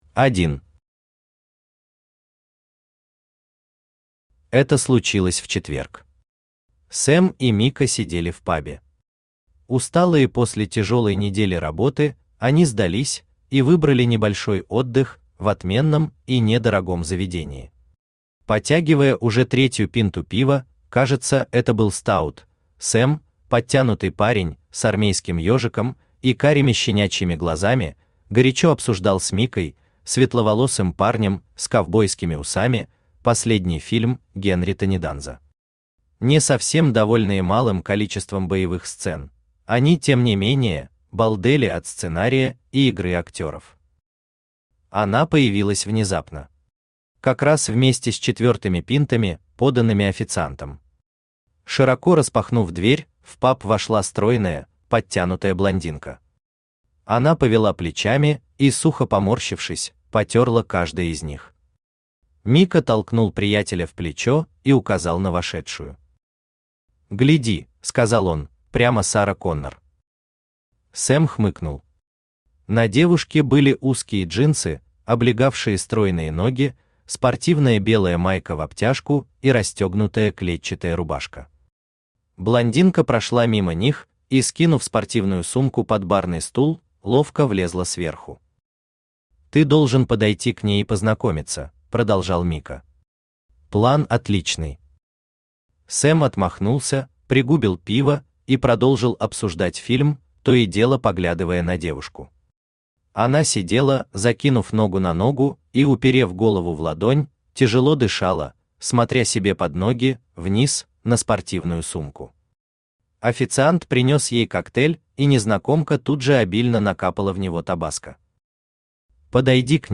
Аудиокнига Уикенд | Библиотека аудиокниг
Aудиокнига Уикенд Автор Александр Иванович Суханов Читает аудиокнигу Авточтец ЛитРес.